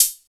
62 HAT.wav